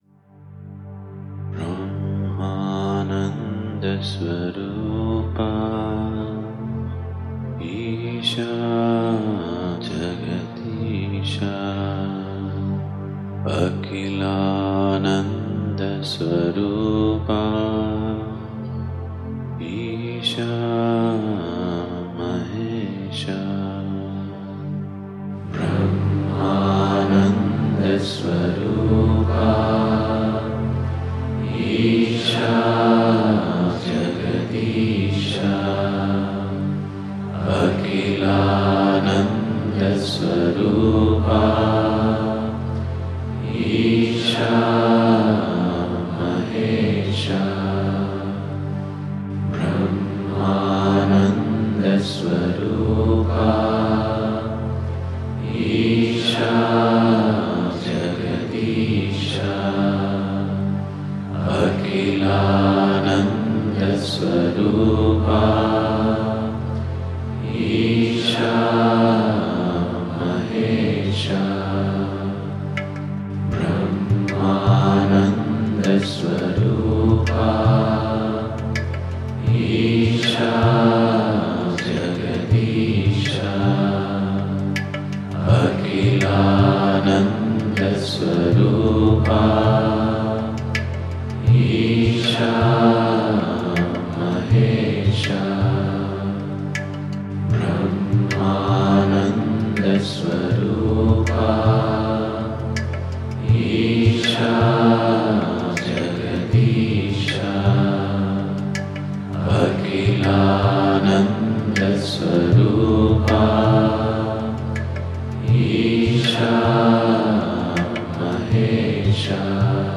像这样的经过圣化的背景声音，同样可以确保生命在撤离时的颠簸得以避免。